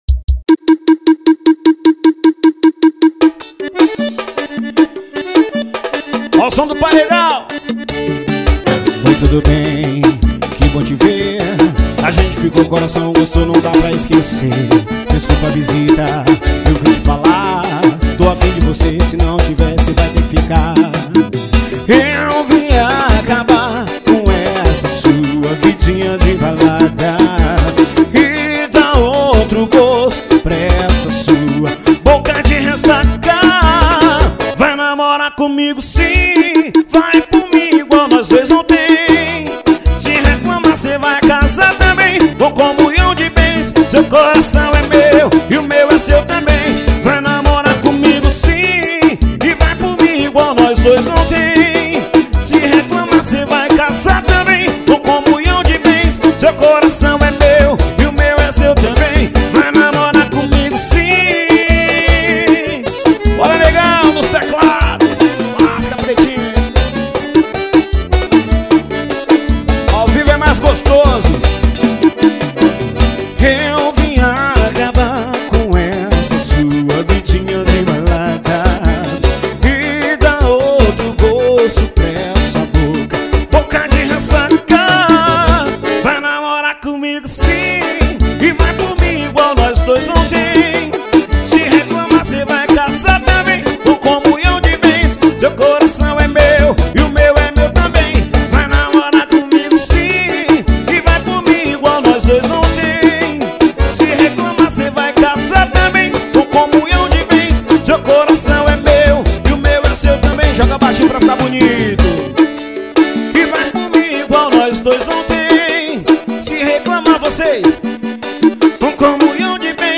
ao vivo sitio gilsão.